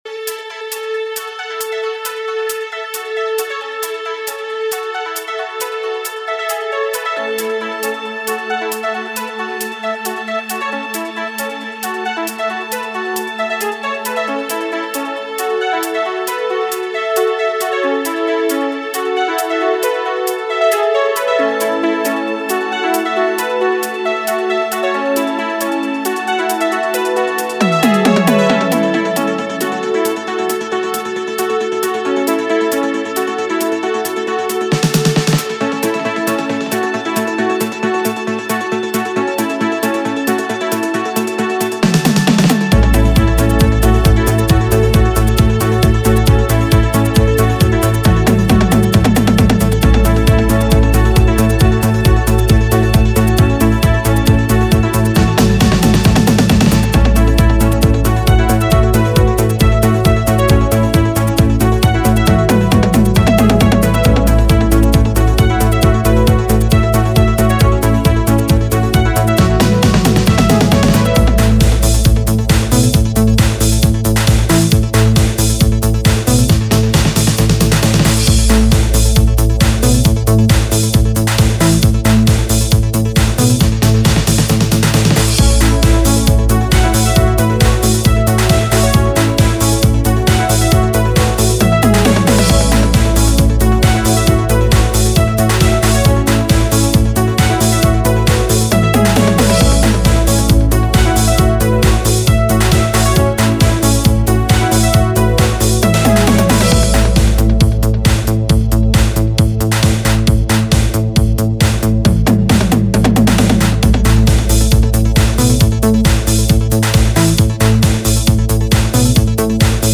It’s a workout mix!
it’s a high energy Italo workout, quite literally.